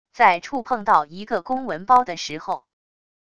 在触碰到一个公文包的时候wav音频